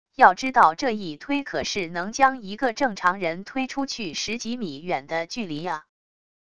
要知道这一推可是能将一个正常人推出去十几米远的距离啊wav音频生成系统WAV Audio Player